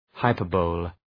Προφορά
{haı’pɜ:rbəlı}